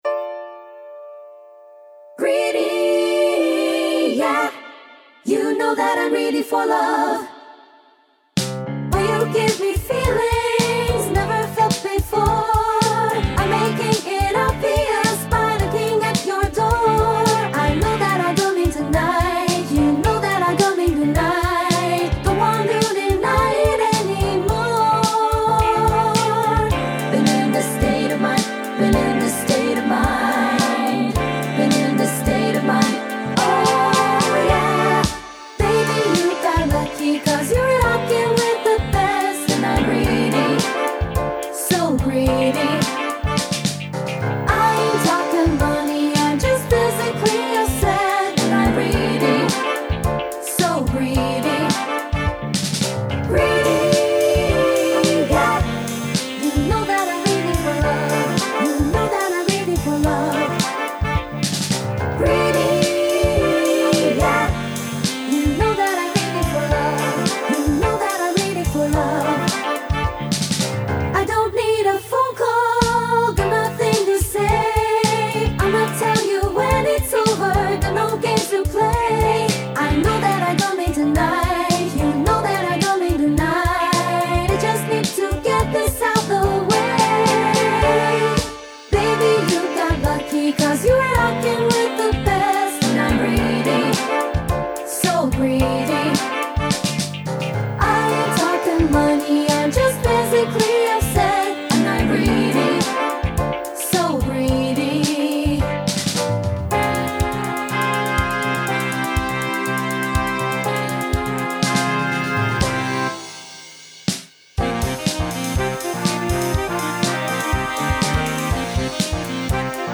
Show Choir Music
Treble/TB